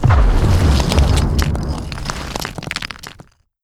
rock_burst.wav